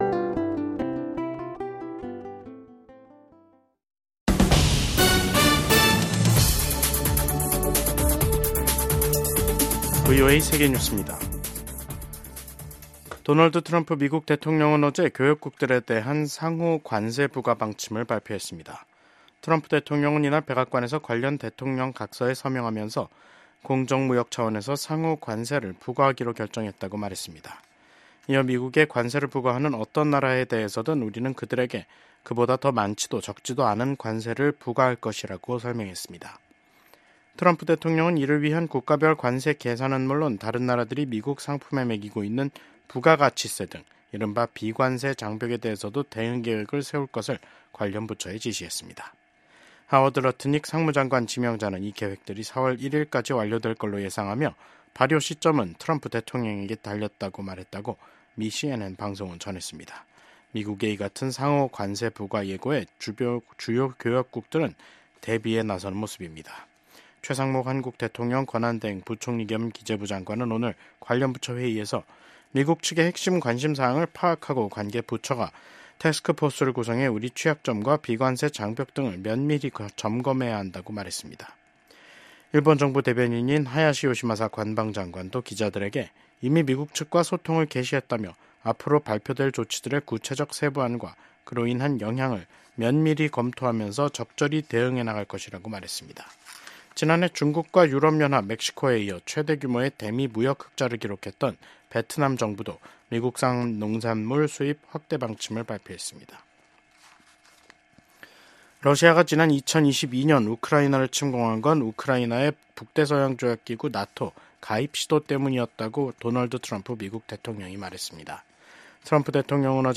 VOA 한국어 간판 뉴스 프로그램 '뉴스 투데이', 2025년 2월 14일 2부 방송입니다. 북한이 남북 화해의 상징인 금강산 관광지구 내 이산가족면회소를 철거 중인 것으로 파악됐습니다. 미국 군함을 동맹국에서 건조하는 것을 허용하는 내용의 법안이 미국 상원에서 발의됐습니다.